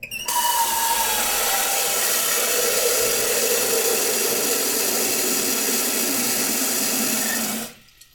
鍋に水を入れる２
put_water_in_pot2.mp3